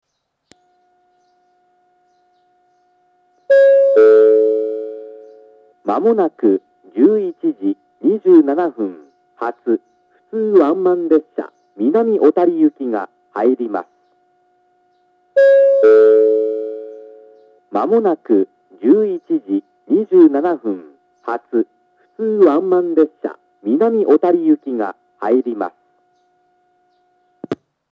１番線下り接近予告放送 11:27発普通ワンマン信濃大町行の放送です。